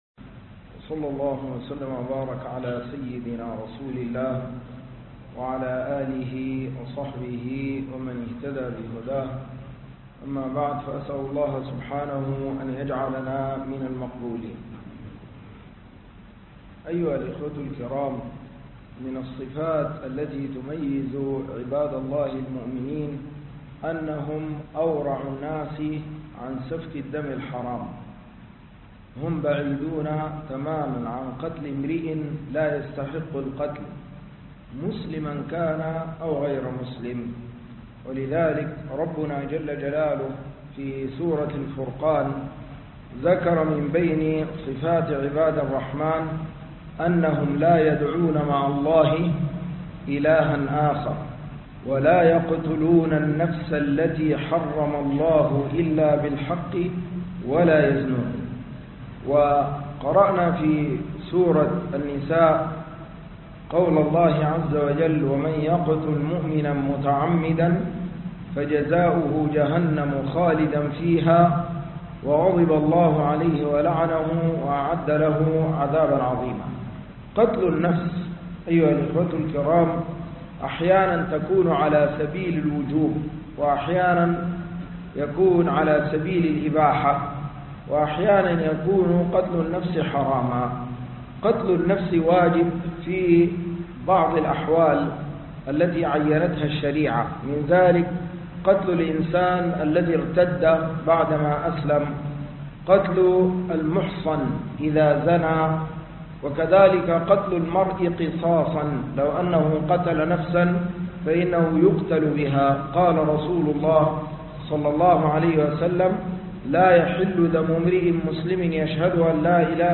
صفات المؤمنين فى القرآن الكريم (التورع عن سفك الدماء) - دروس التراويح - الشيخ عبد الحي يوسف عبد الرحيم